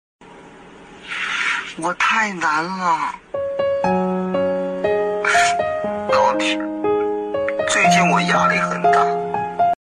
抖音我太难了音效_人物音效音效配乐_免费素材下载_提案神器
抖音我太难了音效免费音频素材下载